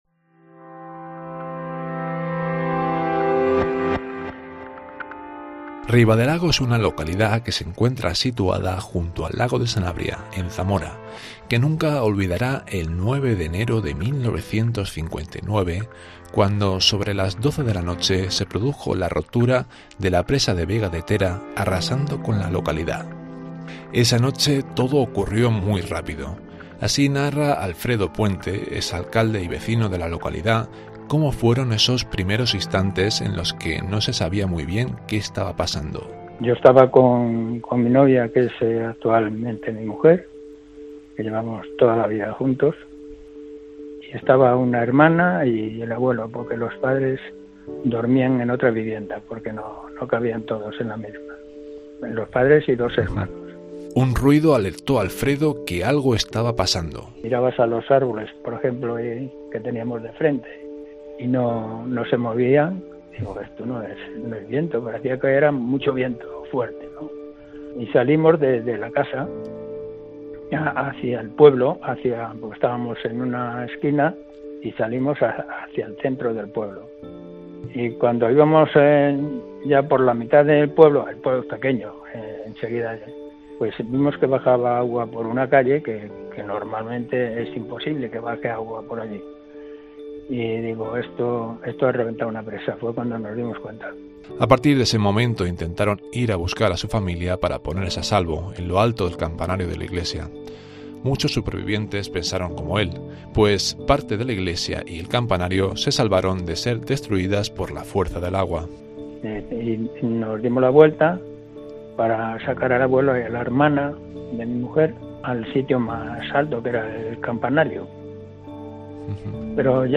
Aquella noche, alrededor de las doce, se produjo la rotura de la presa de Vega de Tera arrasando con la localidad y causando 144 víctimas mortales. Alfredo Puente, exalcalde y vecino de la localidad narra lo sucedido.